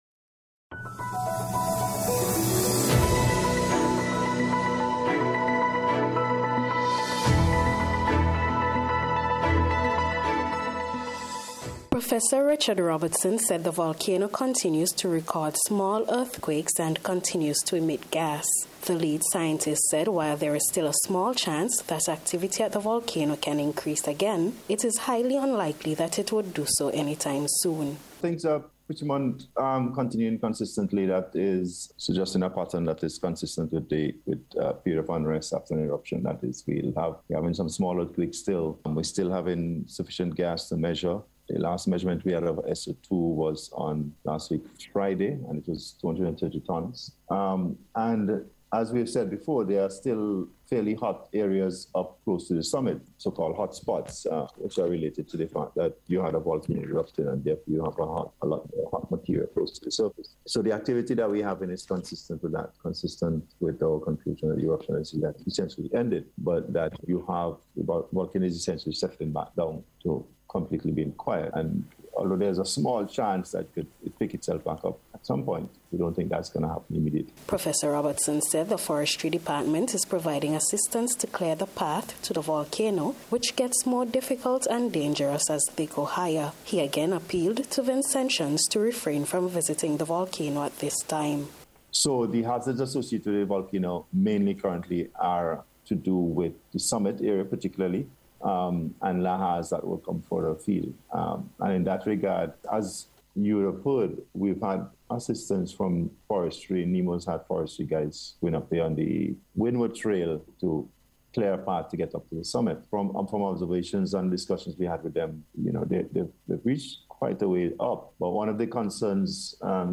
VOLCANO-SETTLE-DOWN-REPORT.mp3